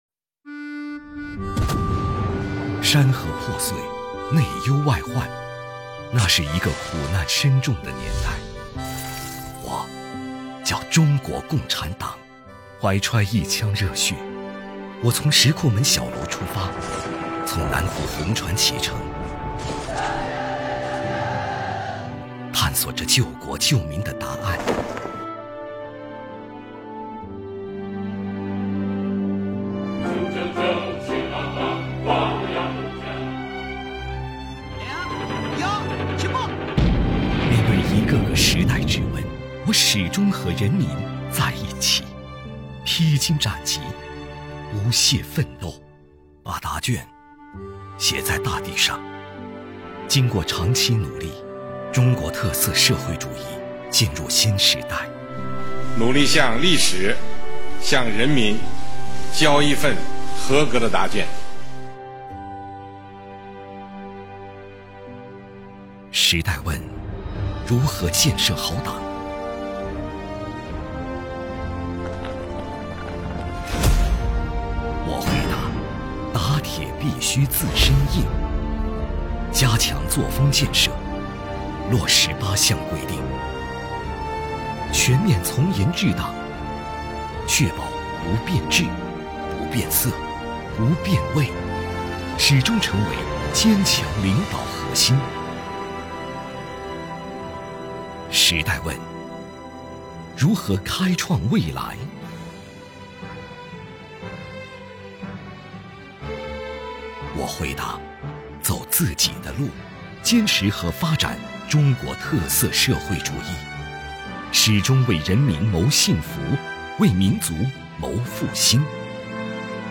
跟随习近平总书记的原声